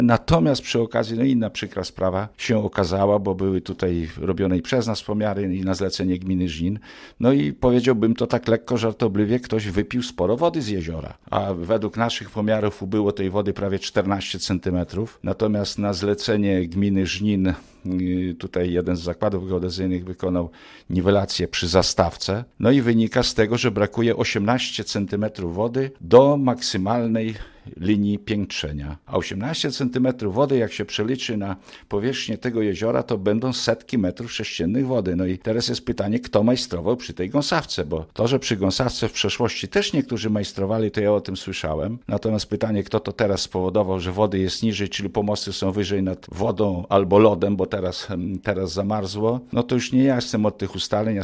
Mówił starosta Zbigniew Jaszczuk.